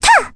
Requina-vox-Landing.wav